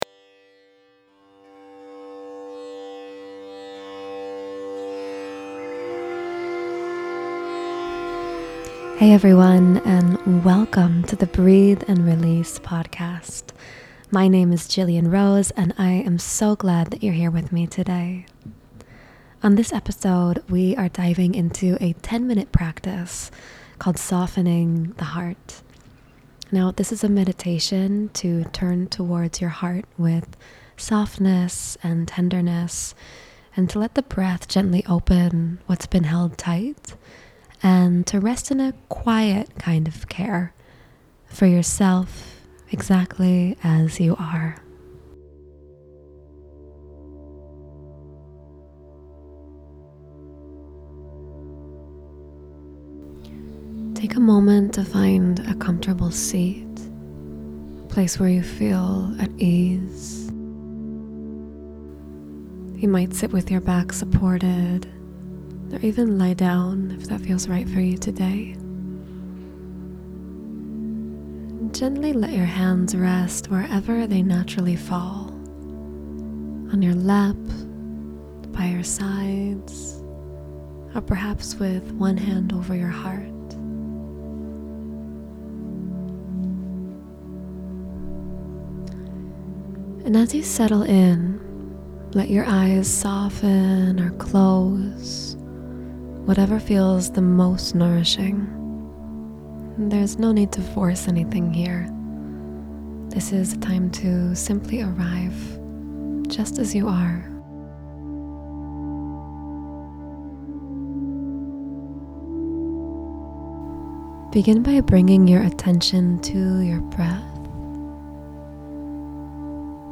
This 10 minute meditation is an invitation to bring in a tenderness and an ease.